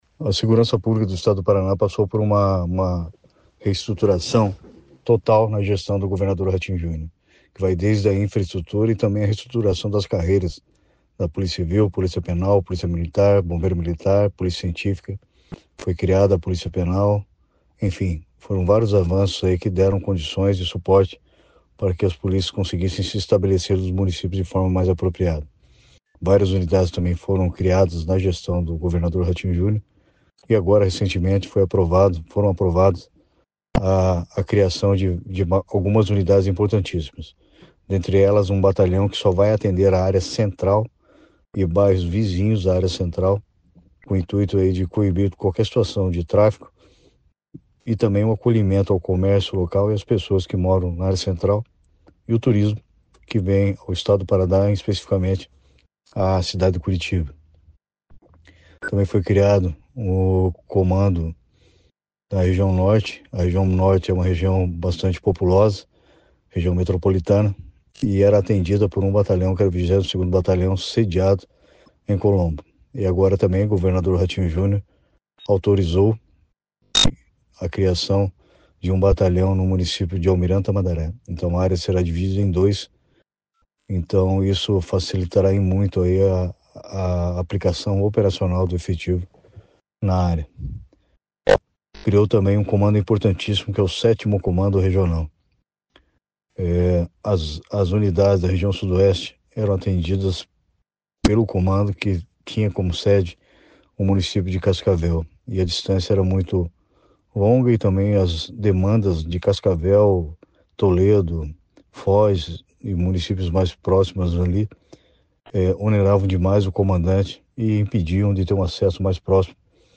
Sonora do secretário Estadual da Segurança Pública, Hudson Teixeira, sobre a criação de novos comandos e batalhões da PM pelo Paraná